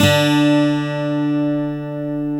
Index of /90_sSampleCDs/Roland L-CD701/GTR_Steel String/GTR_12 String
GTR 12 STR0A.wav